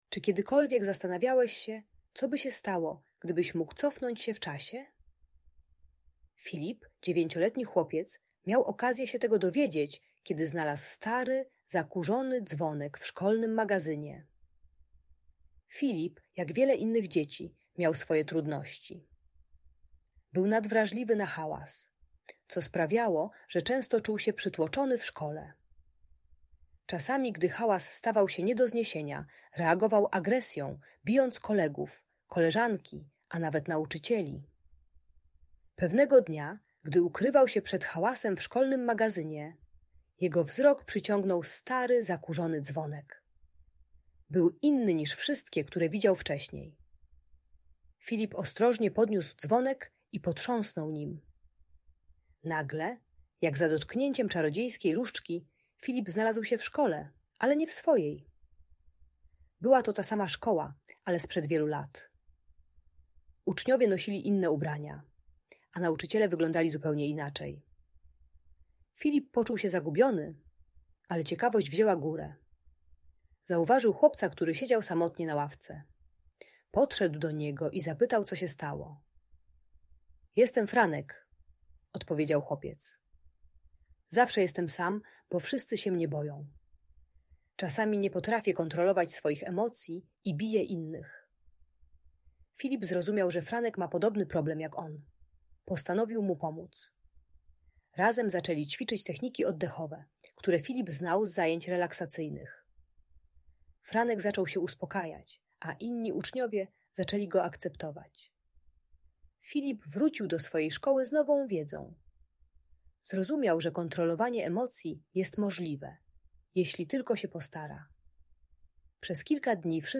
Historia Filipa: Podróże w czasie i nauka radzenia sobie z emocjami - Audiobajka